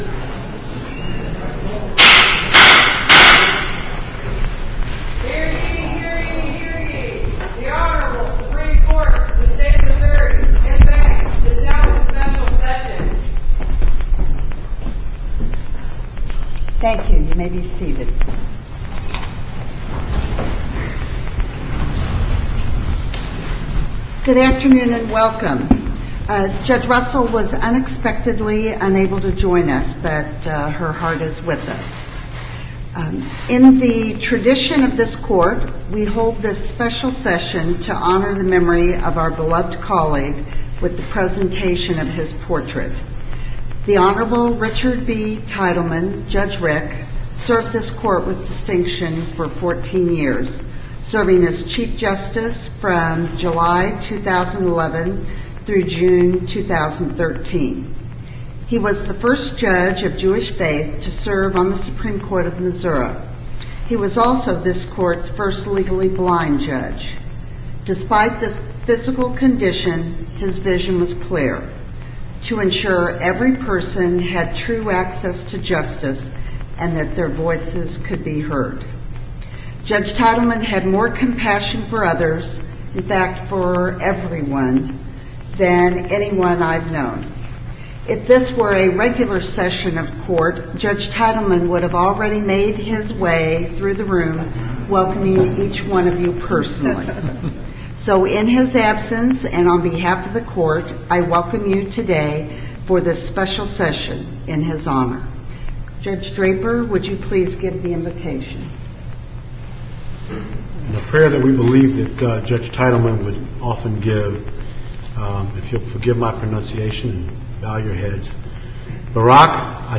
MP3 audio file of the ceremony to unveil the portrait of Judge Richard B. Teitelman